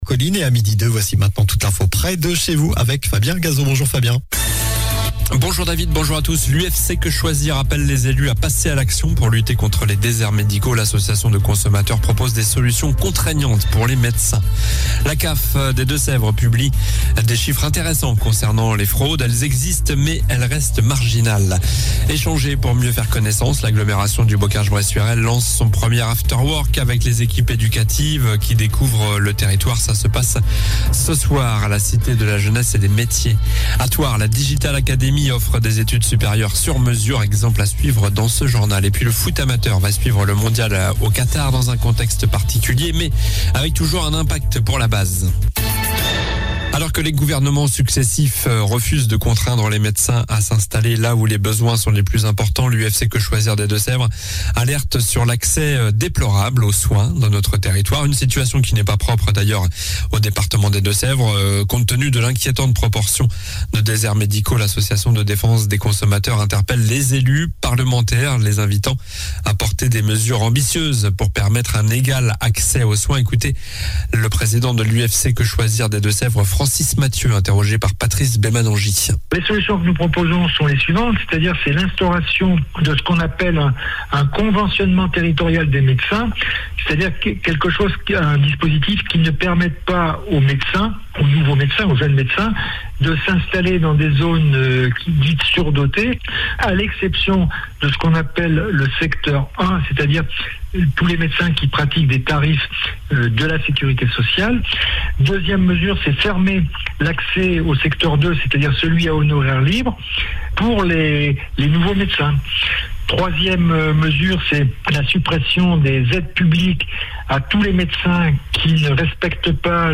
Journal du jeudi 10 novembre (midi)